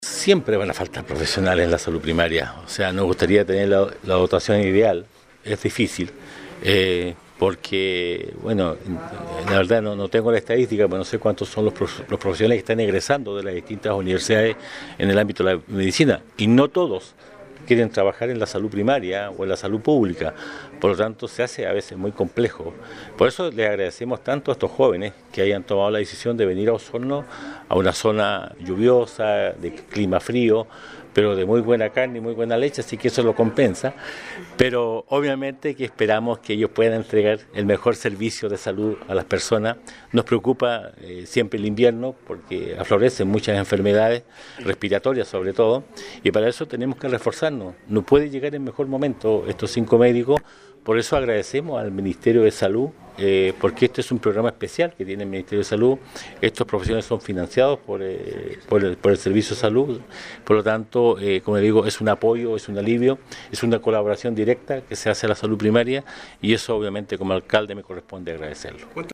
En un acto realizado en Sala de Sesiones, el municipio de Osorno dio la bienvenida a los cinco nuevos médicos y dos odontólogos que llegaron a trabajar a distintos Centros de Salud Familiar de la comuna, lo que es posible gracias al “Programa de Destinación y Formación”, del Ministerio de Salud.